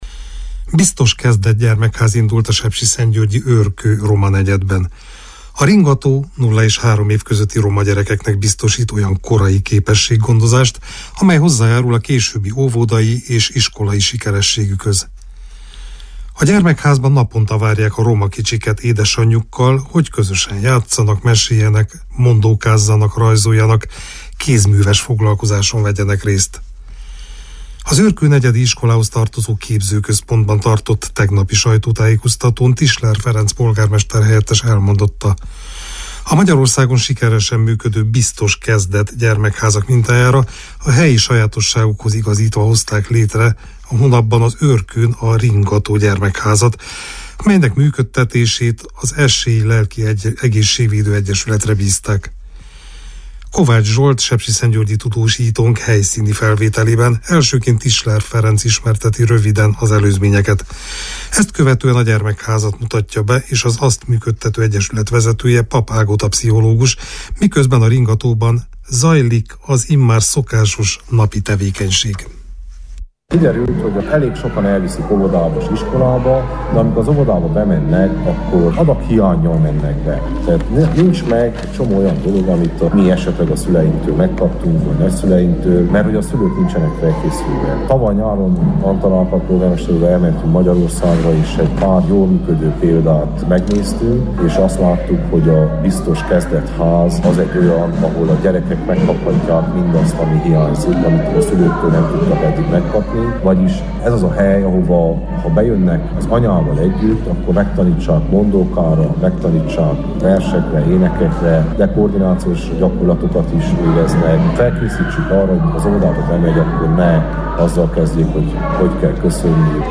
helyszíni felvételében
miközben a Ringató-ban zajlik az immár szokásos napi tevékenység.